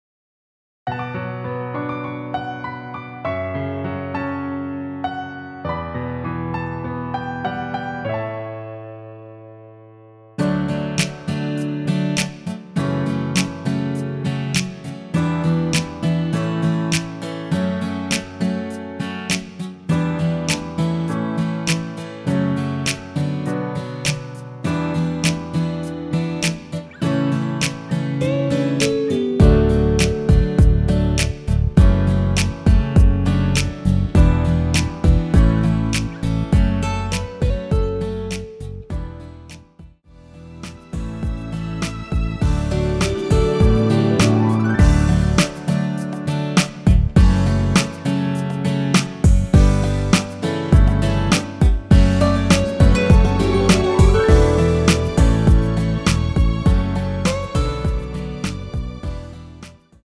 1절후 클라이 막스로 바로 진행되며 엔딩이 너무 길어 4마디로 짧게 편곡 하였습니다.
◈ 곡명 옆 (-1)은 반음 내림, (+1)은 반음 올림 입니다.
앞부분30초, 뒷부분30초씩 편집해서 올려 드리고 있습니다.